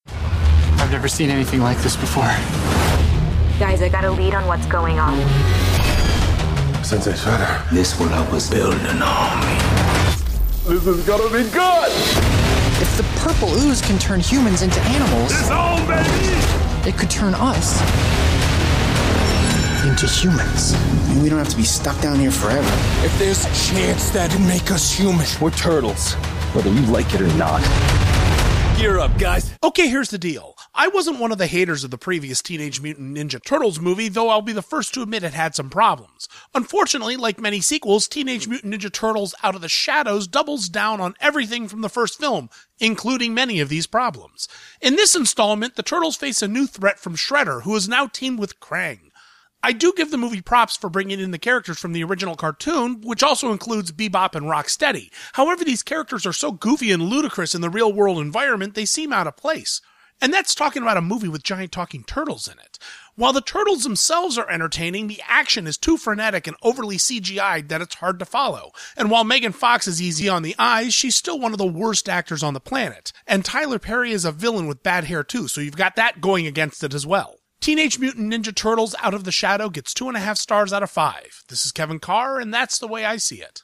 ‘Teenage Mutant Ninja Turtles: Out of the Shadows’ Radio Review